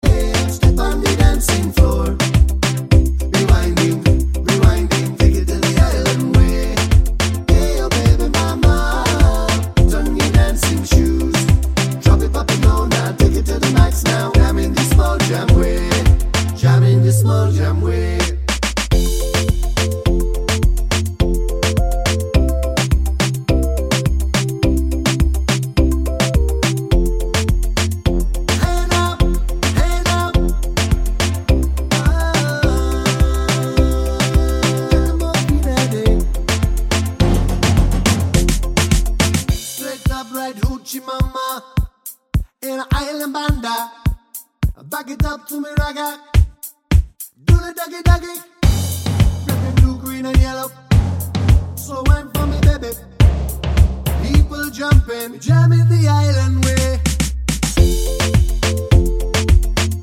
no Backing Vocals Reggae 2:57 Buy £1.50